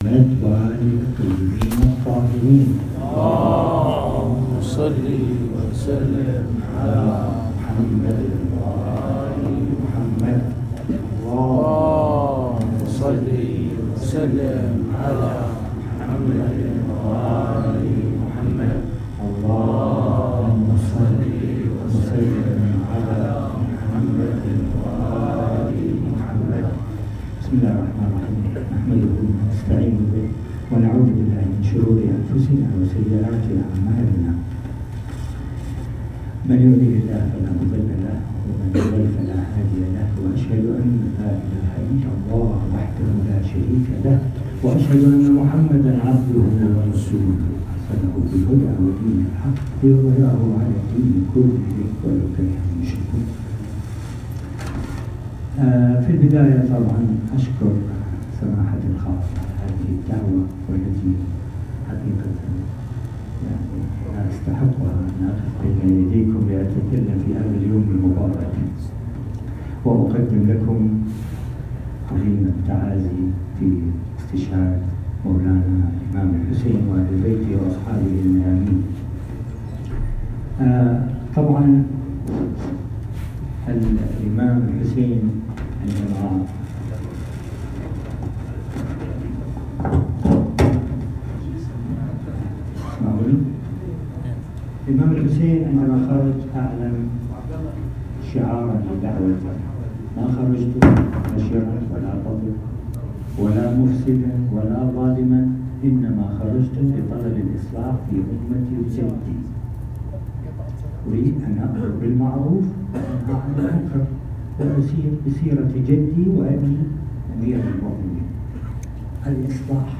خطبة الجمعة في مسجد الإمام السجاد عليه السلام